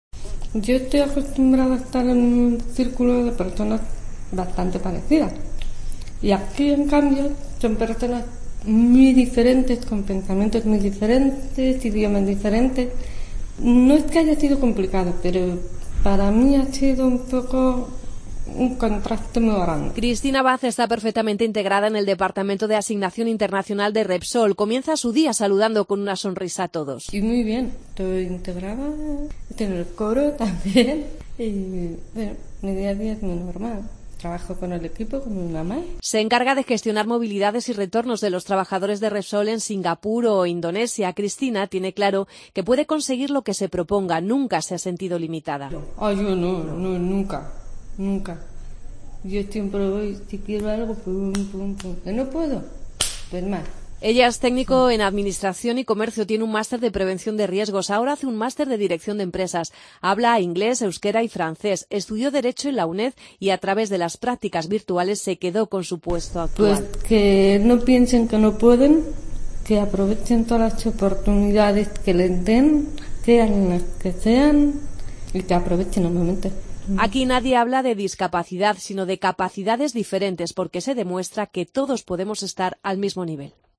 Madrid - Publicado el 03 dic 2014, 15:37 - Actualizado 14 mar 2023, 00:36
Entrevistas en Mediodía COPE